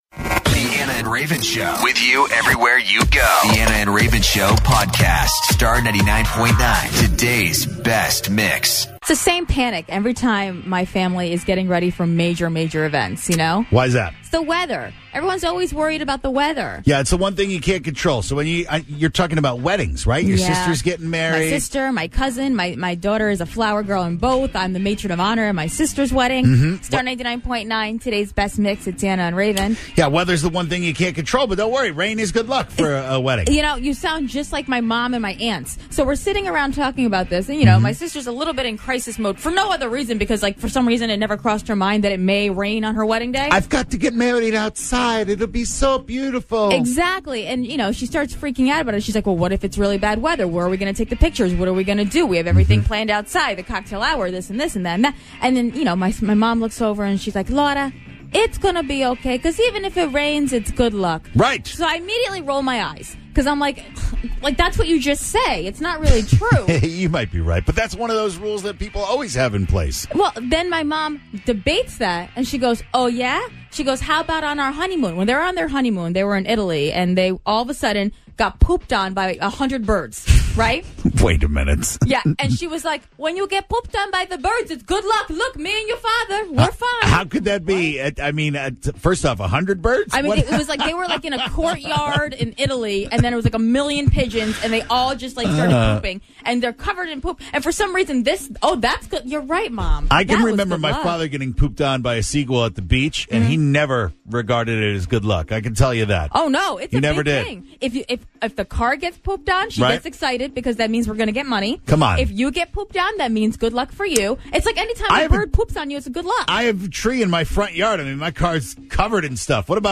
talk with callers around CT about things that they have to do in order to feel lucky.